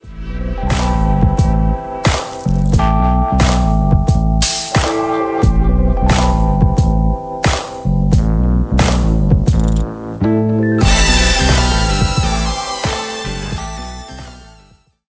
Reduced quality: Yes (59 kbps)